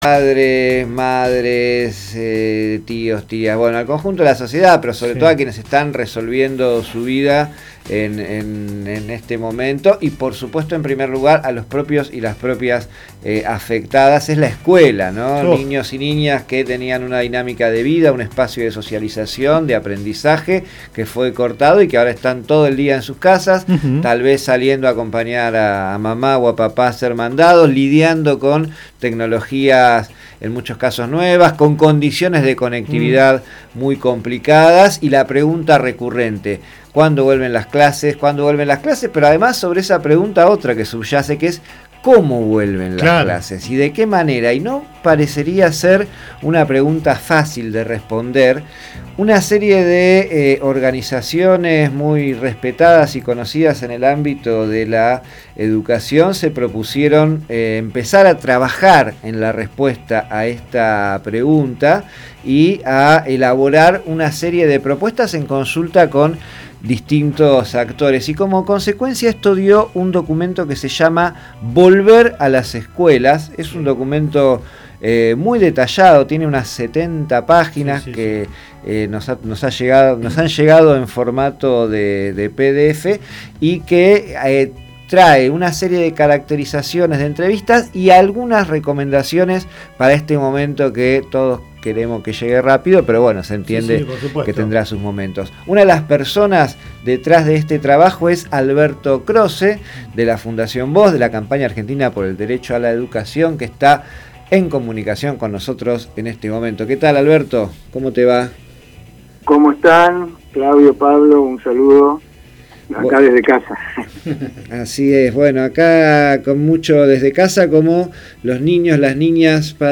En Codo a Codo conversamos con el educador popular